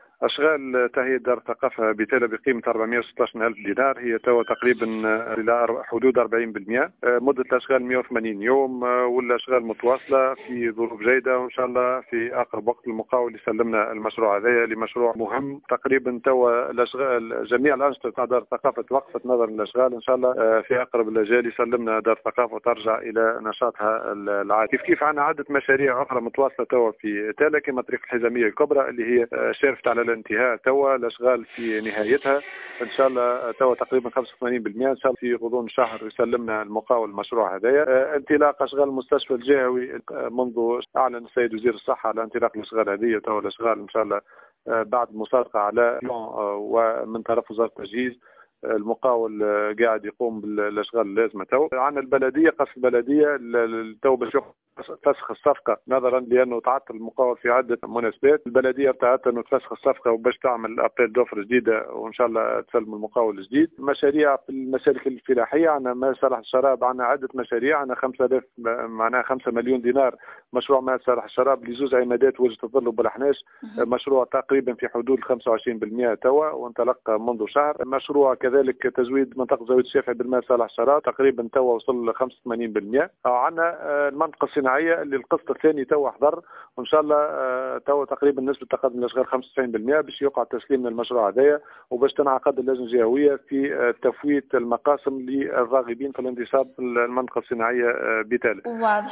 معتمد تالة جوهر الشعباني يقدم اكثر تفاصيل في التسجيل التالي